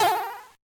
jumpL2.ogg